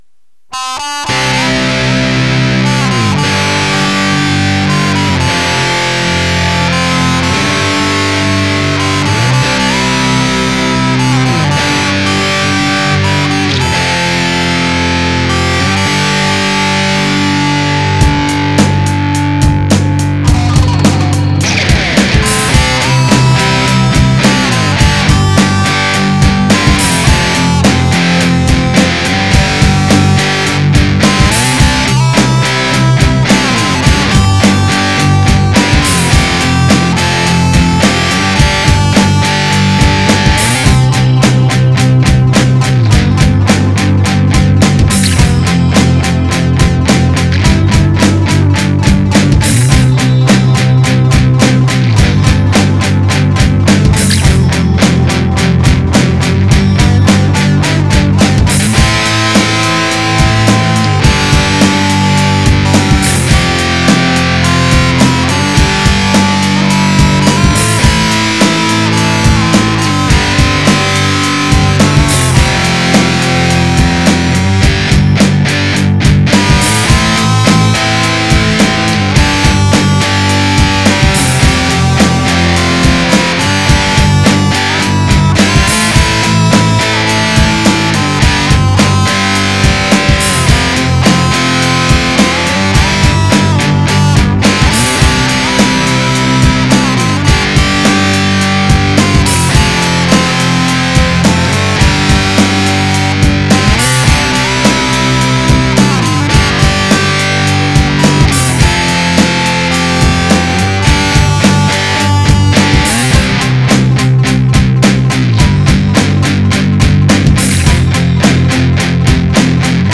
в рок обработке, исполненно на электро гитаре